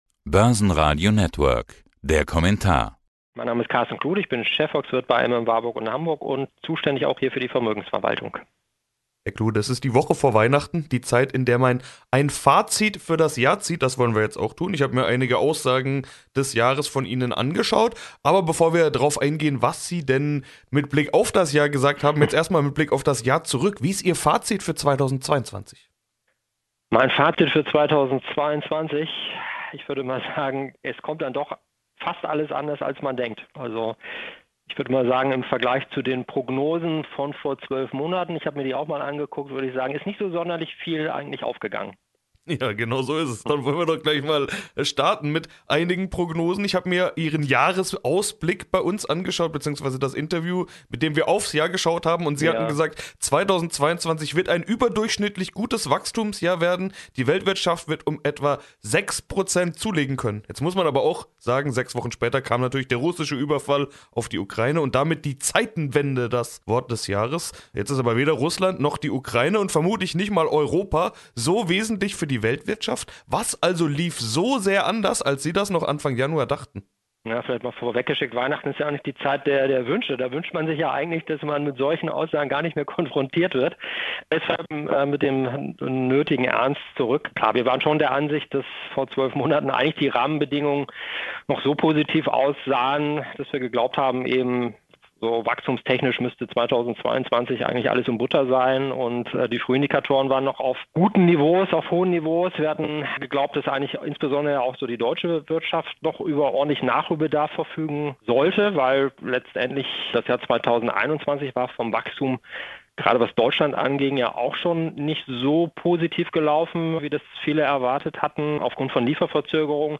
Wie die Inflation sich im kommenden Jahr entwickeln wird und welche Maßnahmen die Notenbank 2023 ergreifen werden, erfahren Sie im Interview.